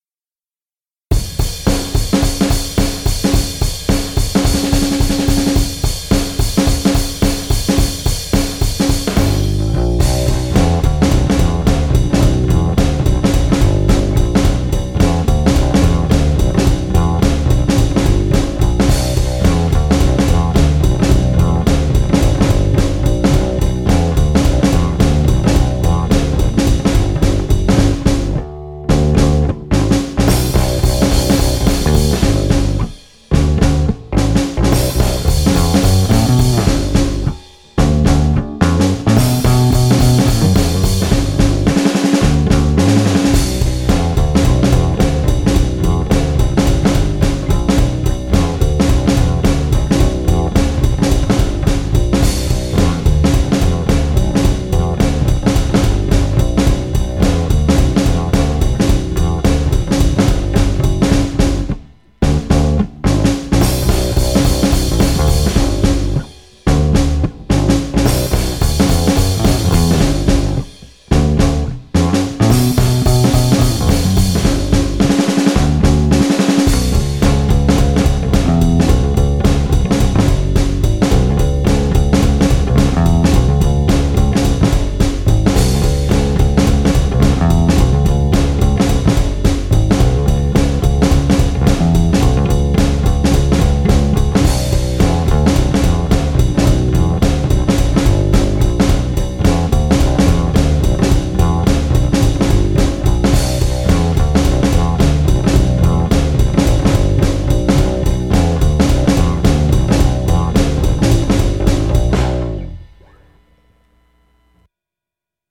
おまけ。練習用トラック